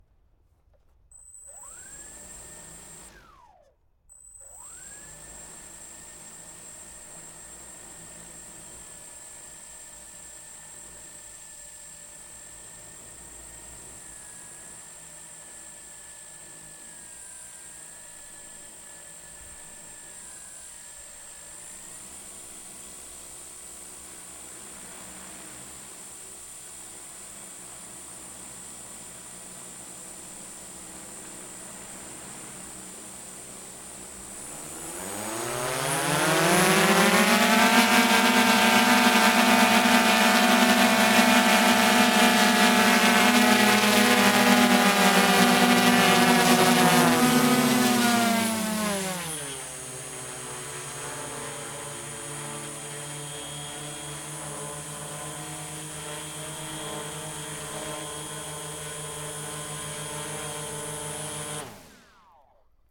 Pole Position - Drone DJI Spreading Wings S900
Drone_S900_t4_ext_startup_steady_rpms_shutdown_stationary_MKH8060.ogg